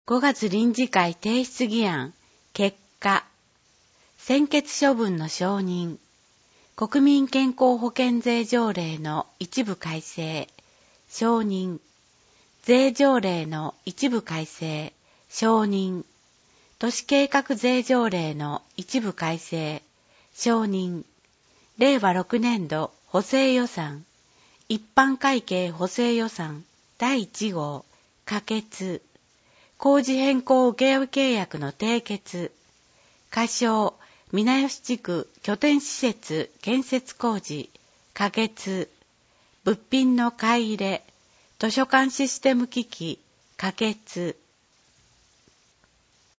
『声の議会広報』は、「みよし議会だより きずな」を音声情報にしたもので、平成29年6月15日発行の第110号からボランティア団体「やまびのこ会」の協力によりサービス提供をはじめました。（一部AI自動音声（テキスト読み上げ）ソフト「VOICEVOX Nemo」を使用）